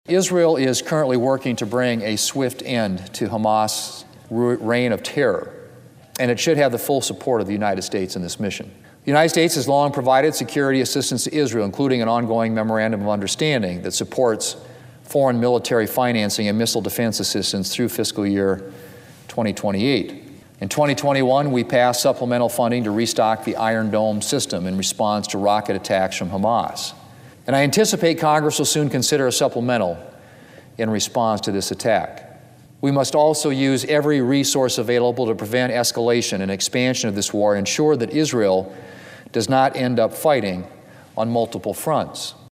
During a recent speech (Oct. 17, 2023) on the floor of the United States Senate, South Dakota Senator John Thune condemned the attacks on Israel by Hamas terrorists.